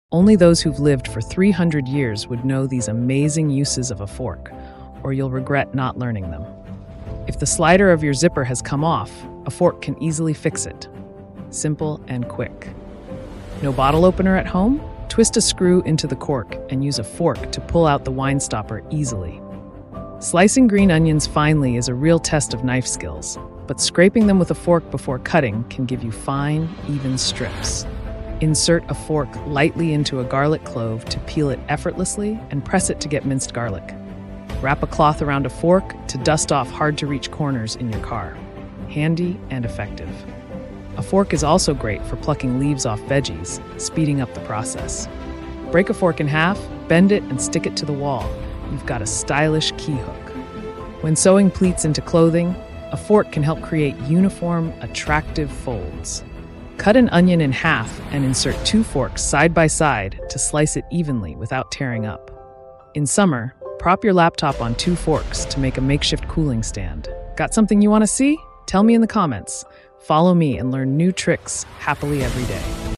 how to bird whistle with sound effects free download